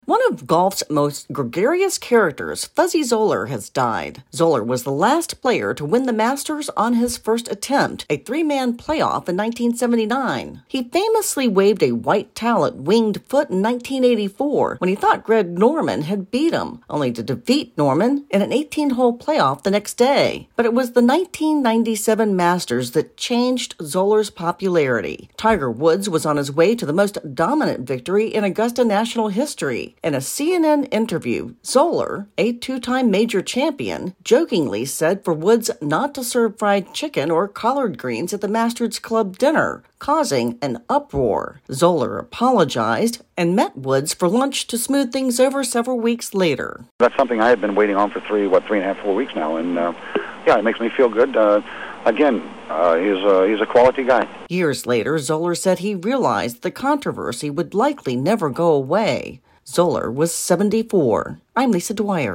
reports on the death of controversial golf champion Fuzzy Zoeller.